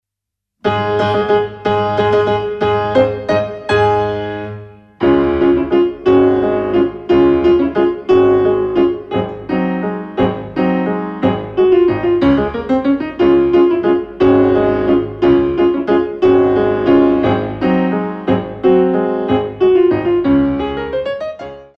Grand allegro 1